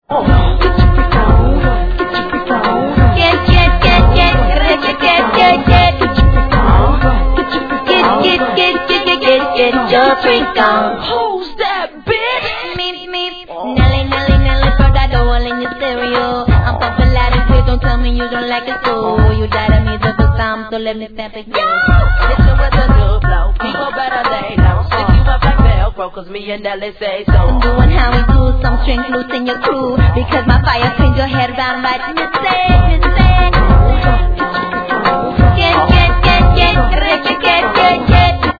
Tag       HIP HOP Promo